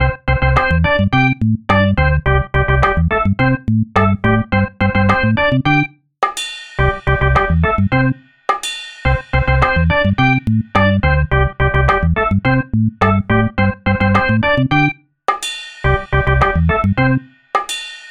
シンプルで陽気なループできるジングル。
おもしろい シンプル のんびり まぬけ 明るい